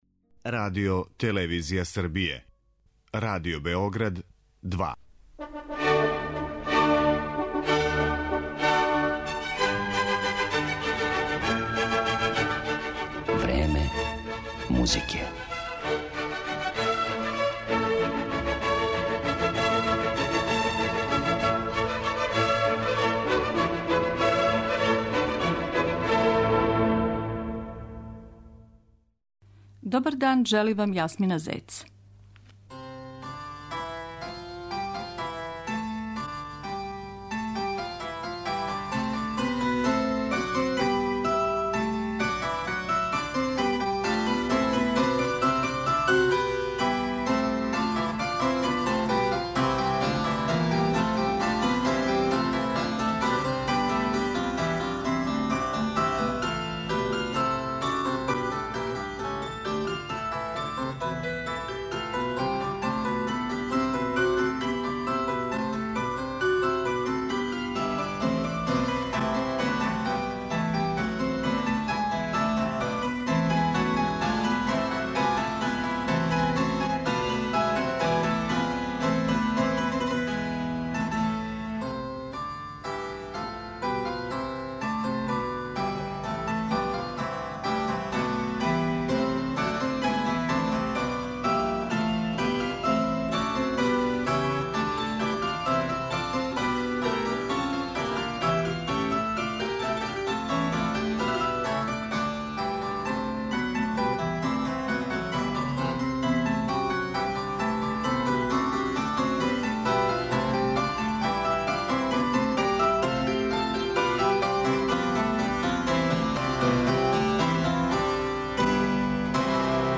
Слушаоци ће моћи да чују избор прелудијума и фуга Јохана Себастијана Баха из прве свеске збирке "Добро темперовани клавир".